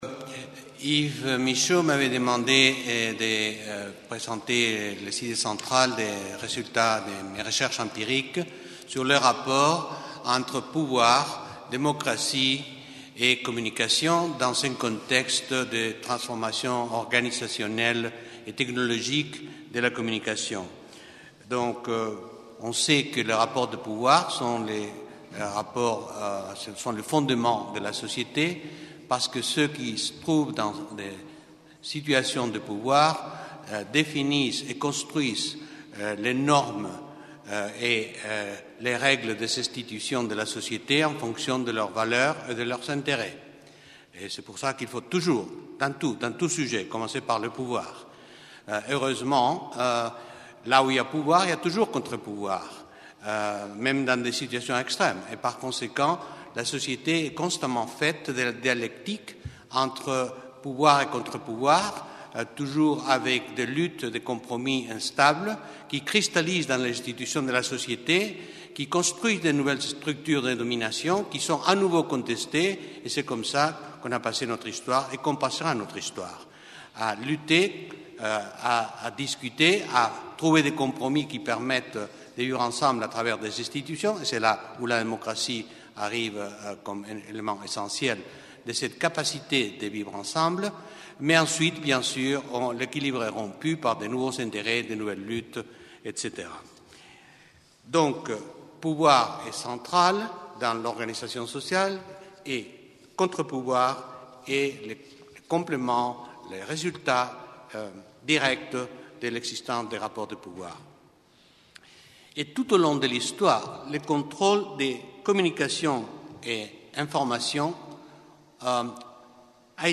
Entretiens du Jeu de Paume : Conférences inaugurales | Canal U